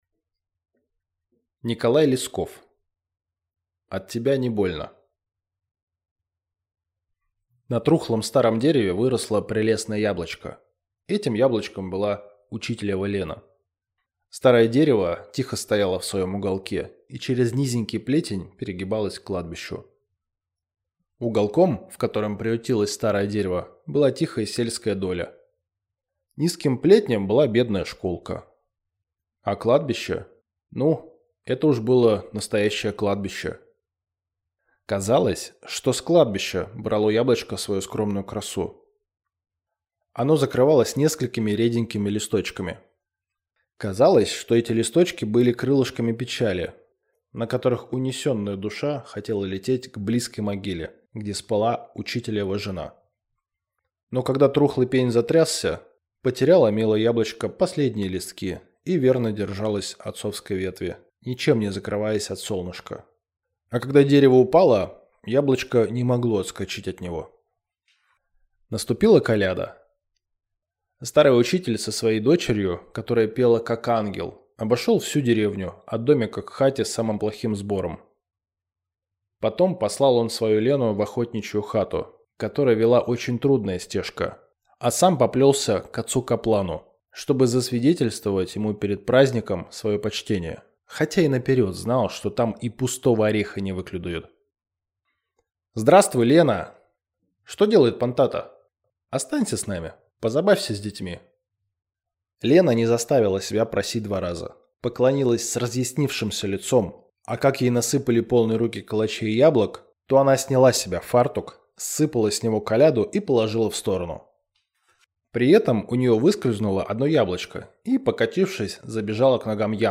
Аудиокнига От тебя не больно | Библиотека аудиокниг